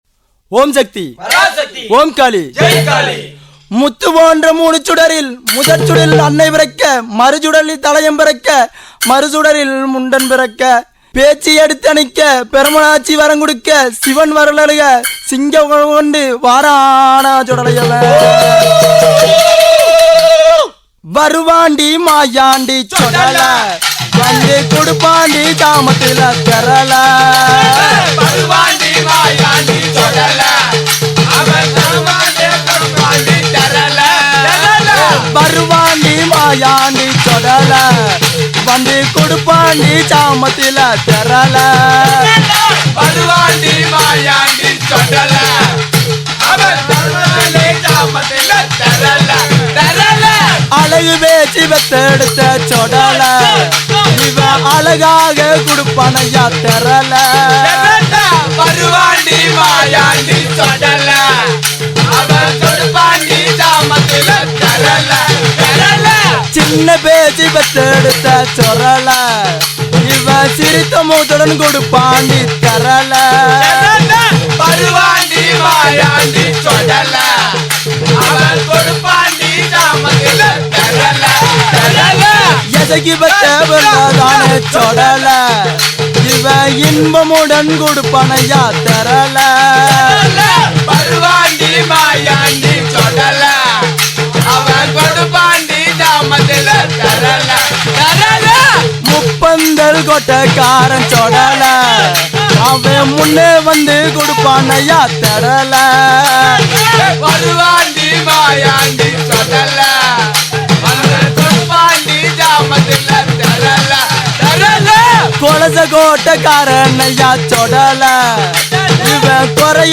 Tamil devotional album